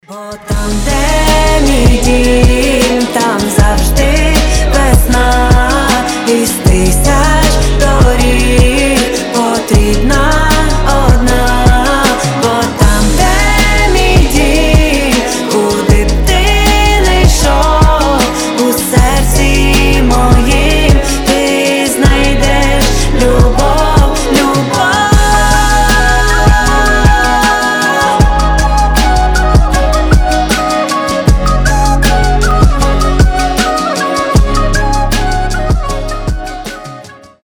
• Качество: 320, Stereo
душевные
украинские